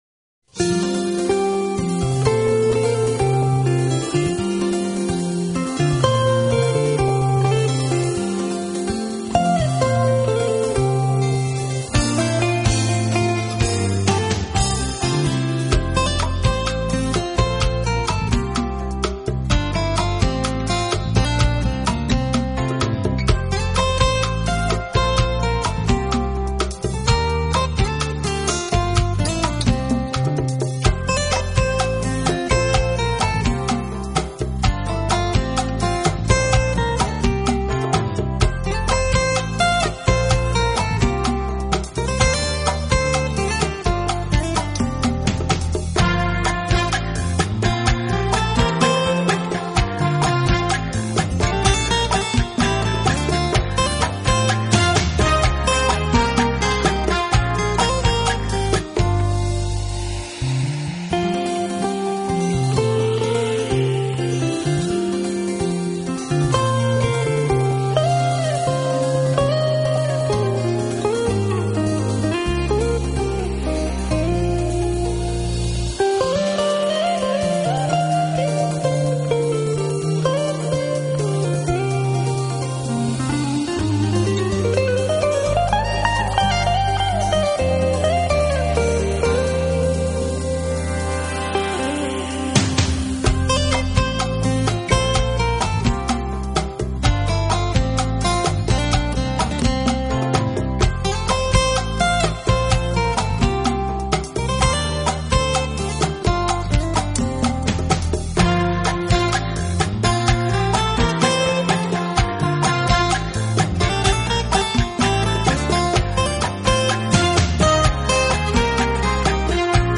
类型: Smooth jazz /Fusion
快明亮，配器干净，是一张值得推荐的Smooth Jazz作品。